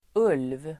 Ladda ner uttalet
Uttal: [ul:v]